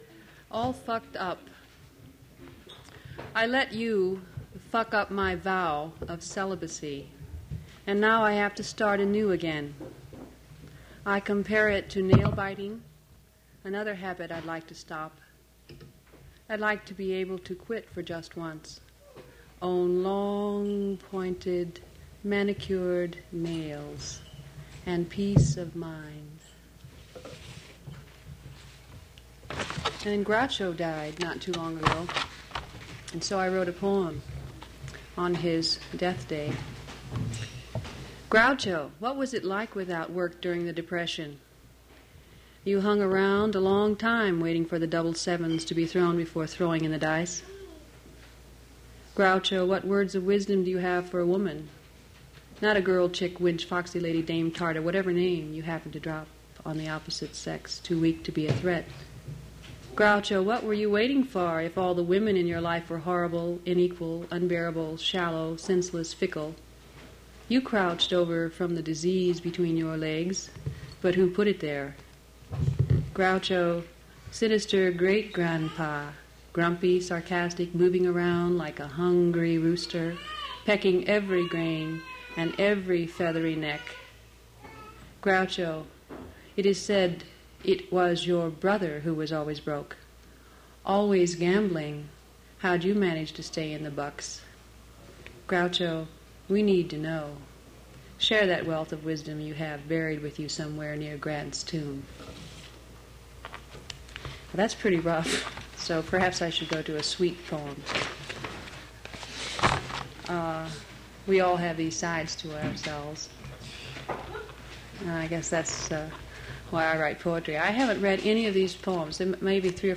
mp3 edited access file was created from unedited access file which was sourced from preservation WAV file that was generated from original audio cassette. Language English Identifier CASS.601 Series River Styx at Duff's River Styx Archive (MSS127), 1973-2001 Note There was supposed to be a first reader for this track but the entire first half was nearly silent.